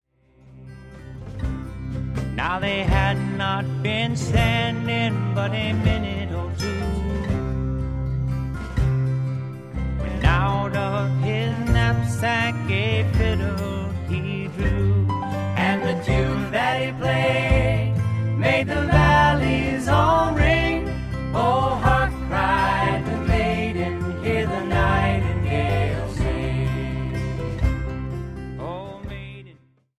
70s Music Singer Songwriter